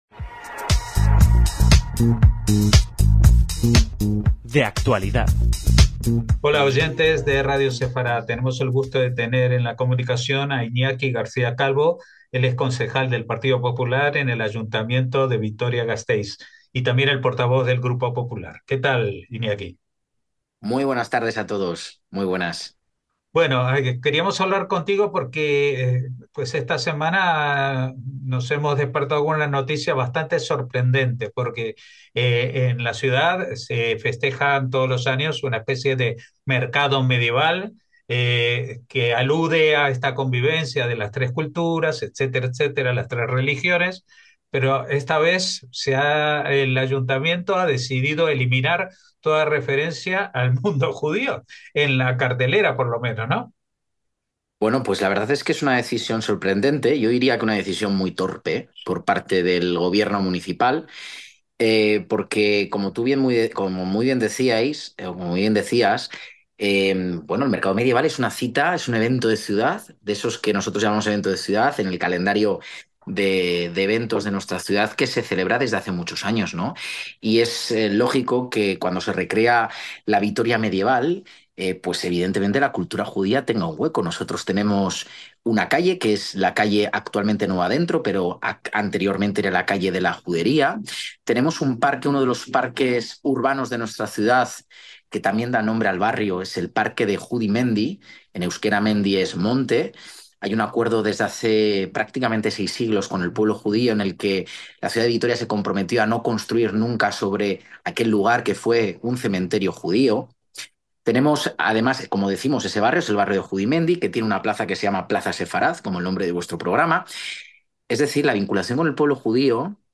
El concejal del PP en dicha corporación y portavoz de su formación en el consistorio, Iñaki García Calvo nos comenta su perplejidad ante una decisión que, dice, fomenta discursos de odio contra los judíos y el propio pasado histórico de la ciudad, que aún conserva en la nomenclatura geográfica muchas referencias como la calle